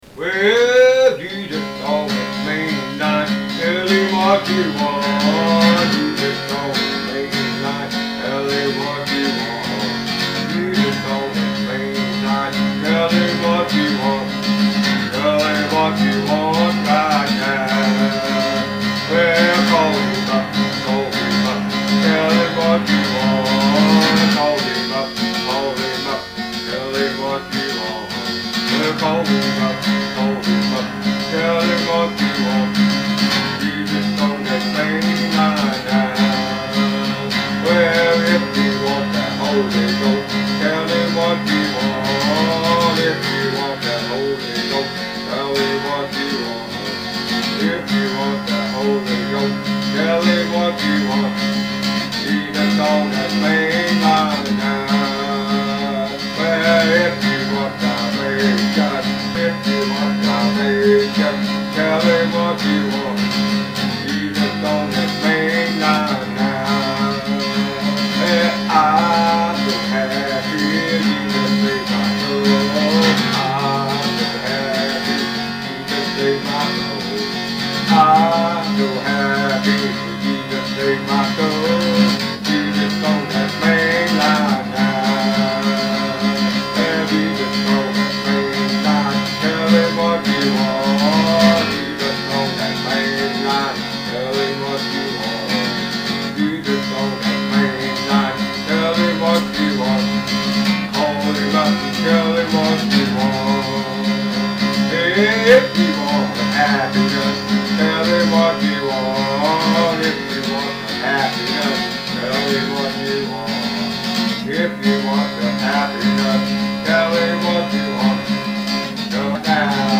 Tags: RELIGION SINGING SERMONS